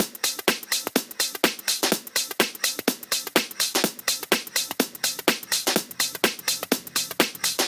• top house fill breaks.wav
top_house_fill_breaks_eEB.wav